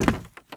STEPS Wood, Creaky, Walk 25.wav